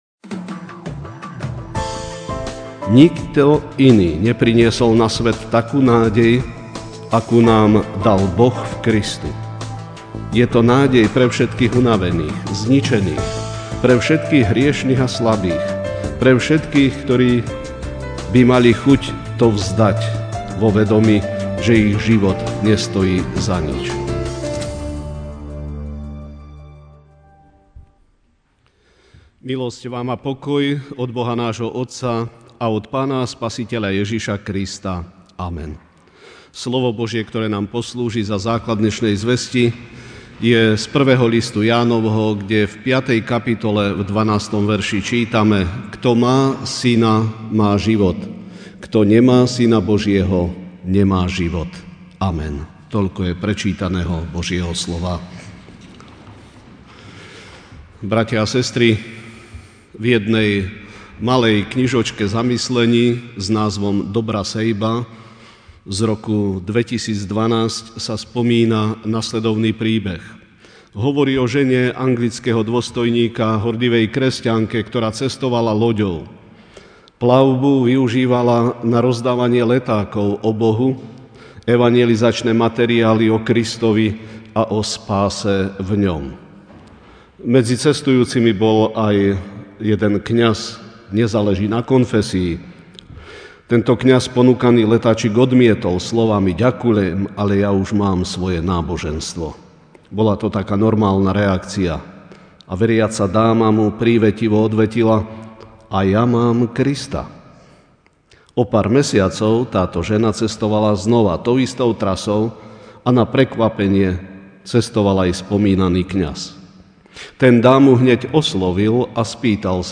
Večerná kázeň: Mať Pána Ježiša (1J 5, 12)Kto má Syna, má život; kto nemá SynaBožieho, nemá život.